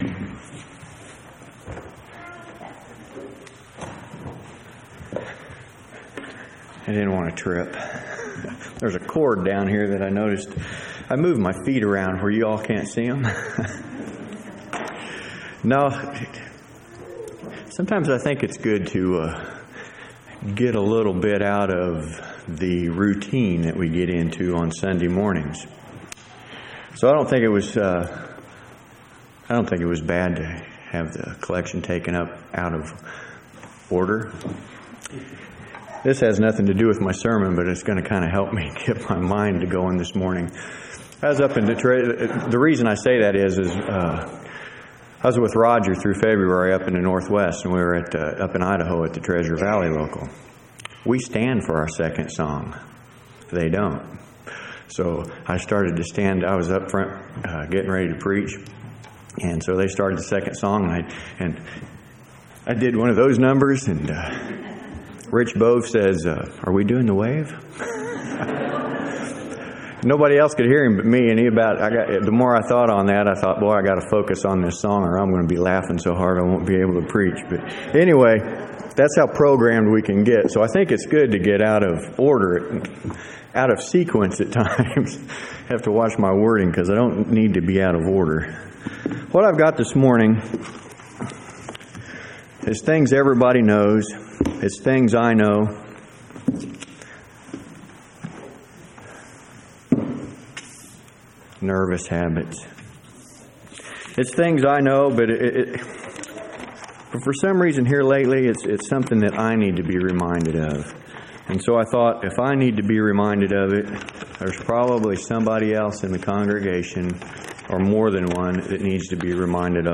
3/26/2006 Location: Temple Lot Local Event